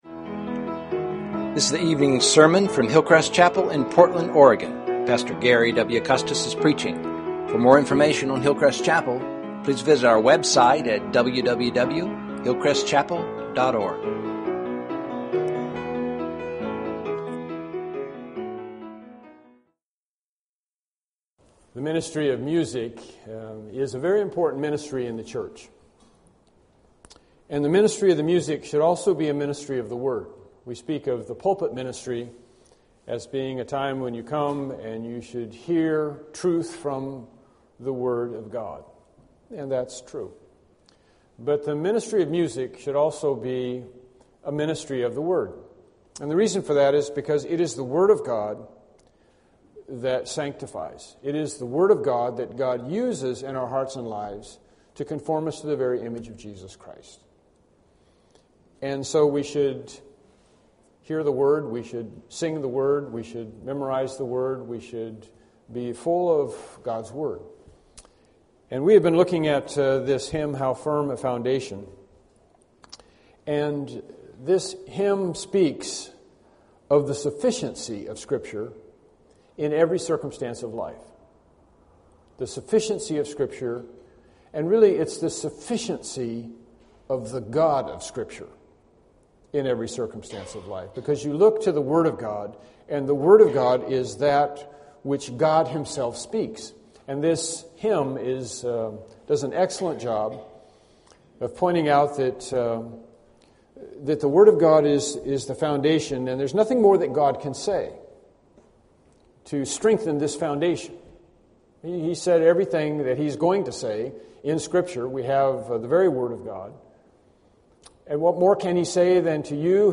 Evening Sermons
Hymn 80 How Firm a Foundation Service Type: Evening Worship Service Topics